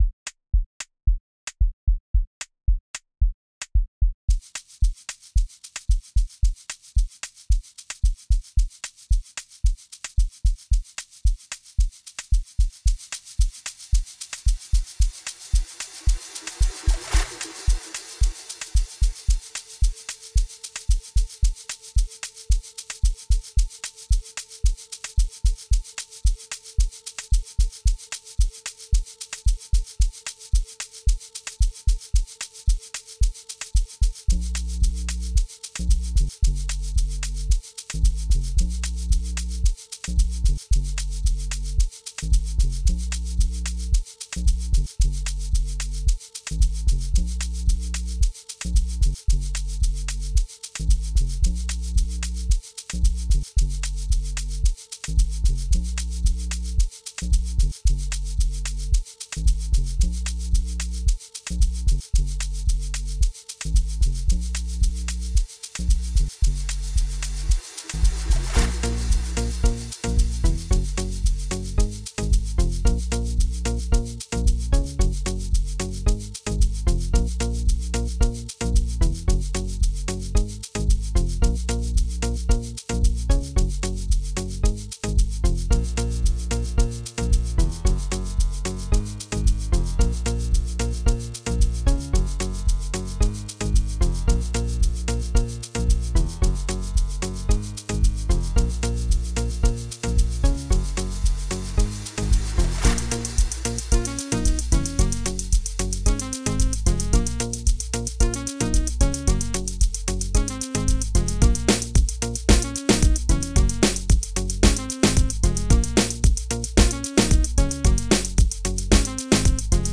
05:16 Genre : Amapiano Size